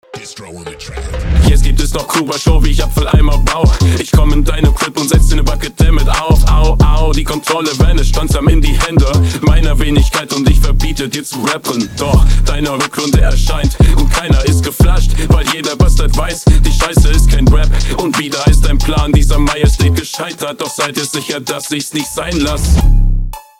Flow ganz passabel.